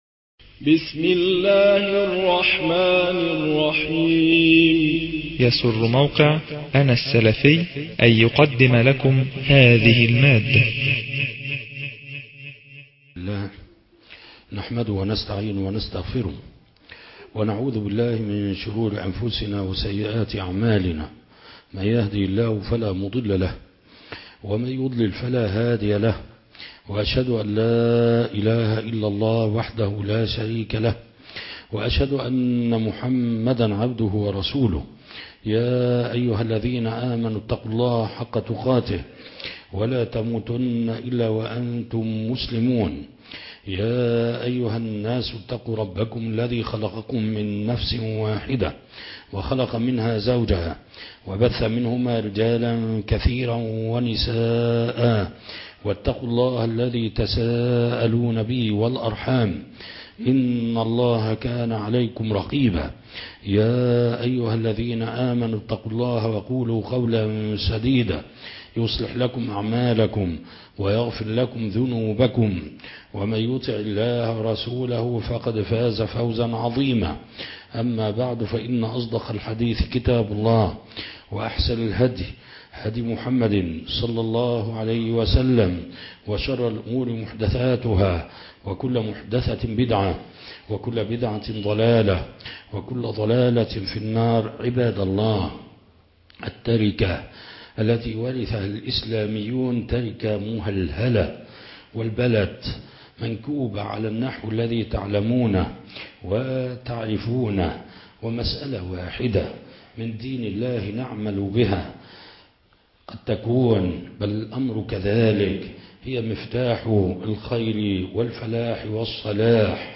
خطبة الجمعة